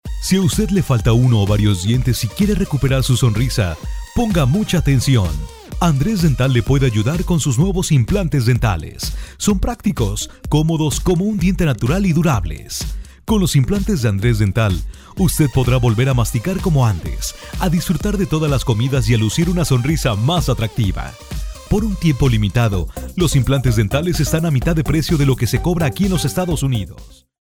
Cuña para emisora de Estados Unidos.
Música sin copyright de Escena Digital.